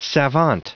Prononciation du mot savant en anglais (fichier audio)
Prononciation du mot : savant